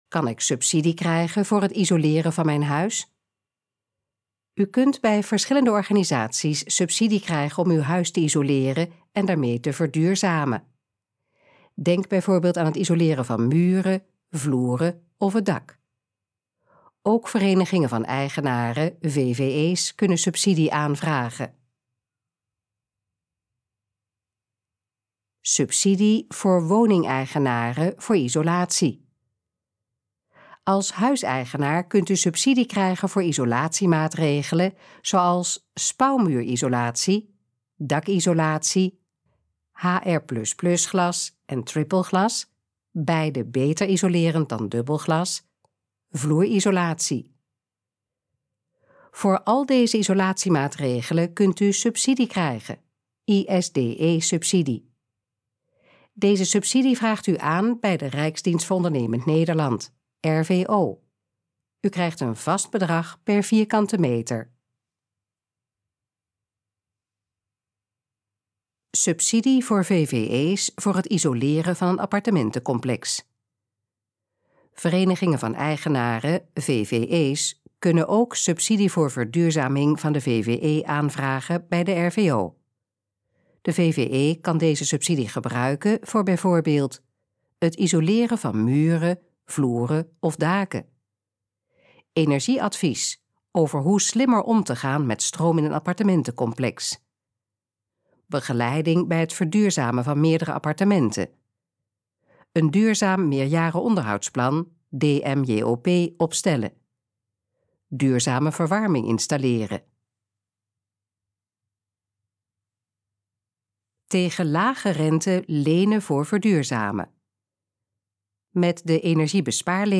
Gesproken versie van: Kan ik subsidie krijgen voor het isoleren van mijn huis?
Dit geluidsfragment is de gesproken versie van de pagina: Kan ik subsidie krijgen voor het isoleren van mijn huis?